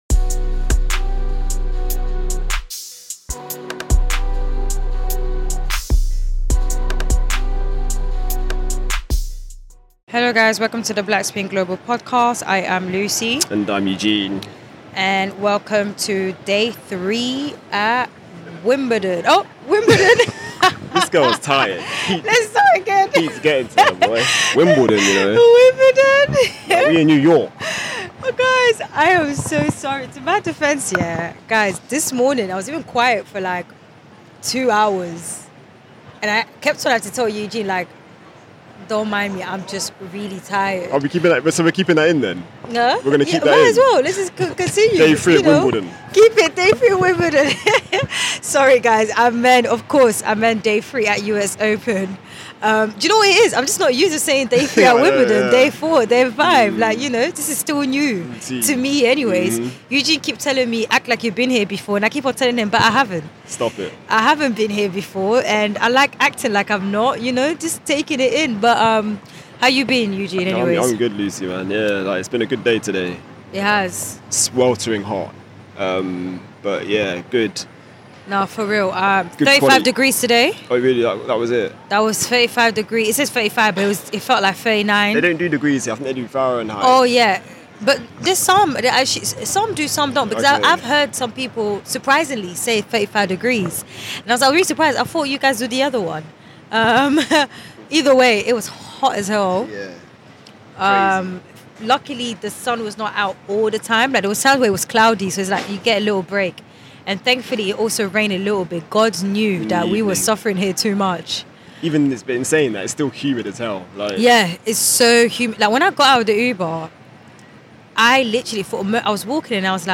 Listen out for interview snippets from Tiafoe, Shelton and Gauff. We also address the Marketa Vondrousova cornrows situation.